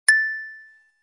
创建于3ML钢琴编辑器。
Tag: 正确的 游戏的效果 丁声